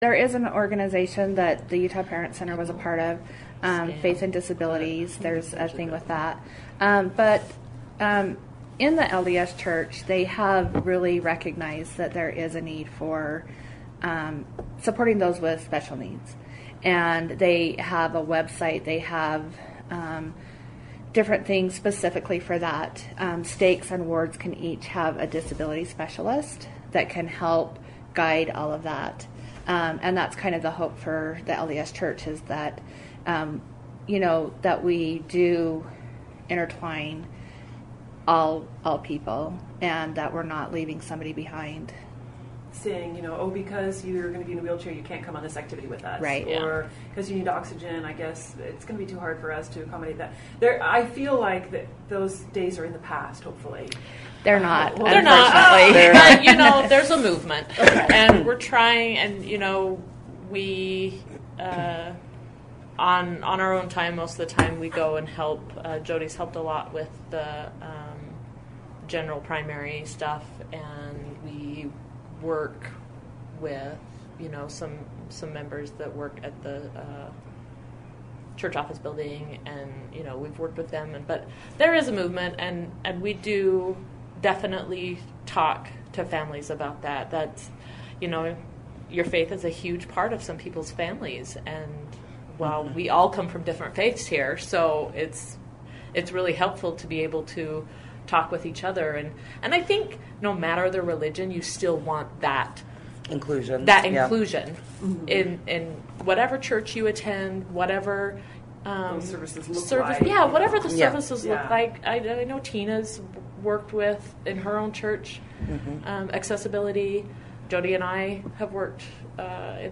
Interviews: church and children with disabilities